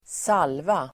Uttal: [²s'al:va]